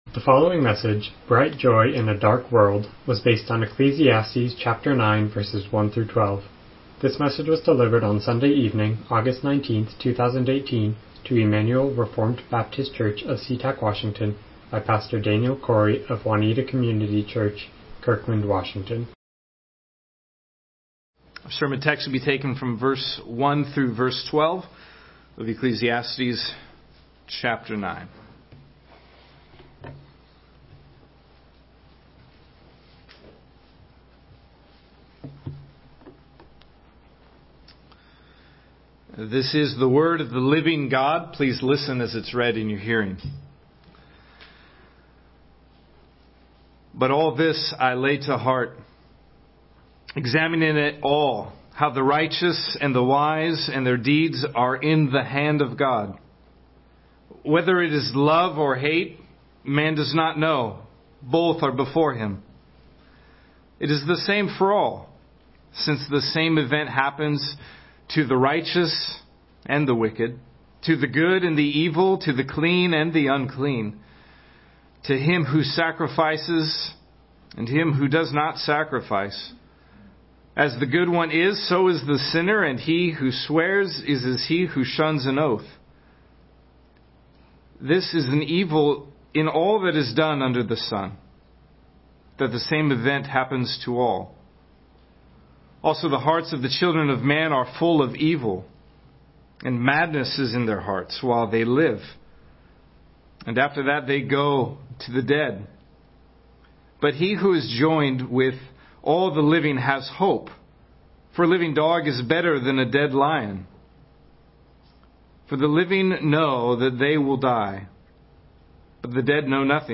Miscellaneous Passage: Ecclesiastes 9:1-12 Service Type: Evening Worship « A Weight That Even Samson Couldn’t Lift Old Testament Survey